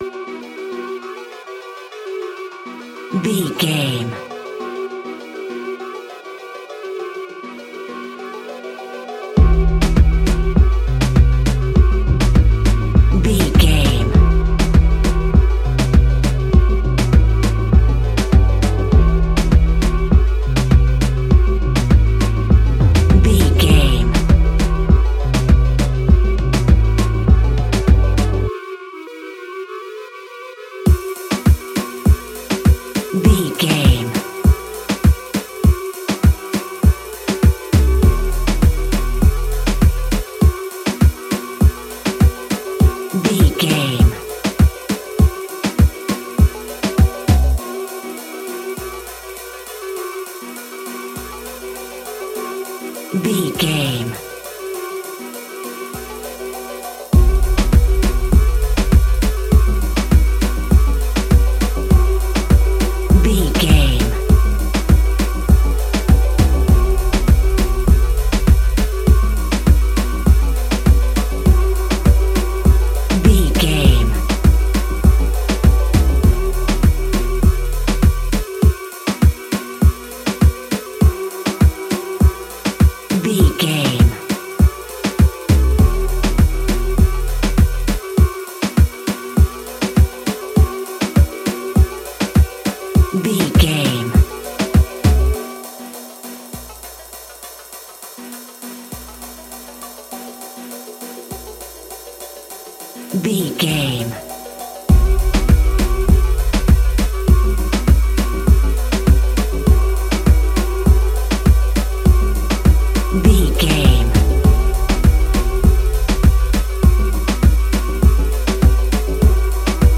Classic reggae music with that skank bounce reggae feeling.
E♭
instrumentals
laid back
chilled
off beat
drums
skank guitar
hammond organ
percussion
horns